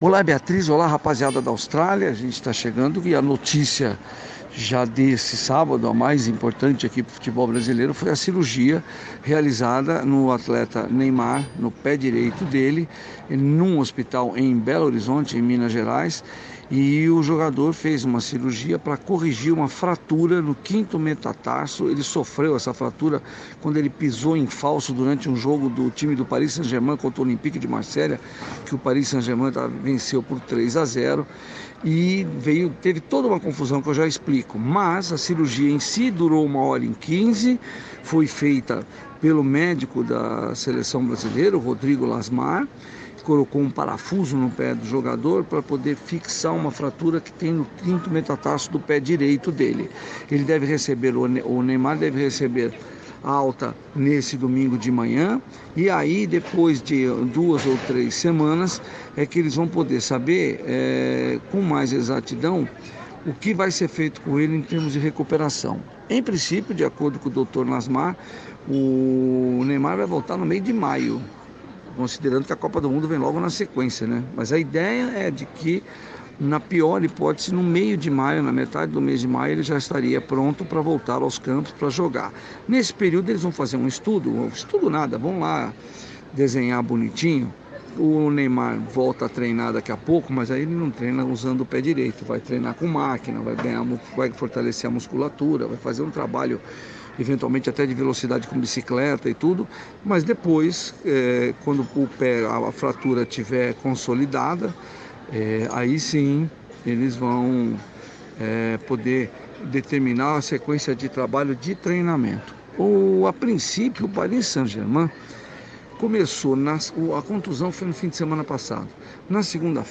boletim semanal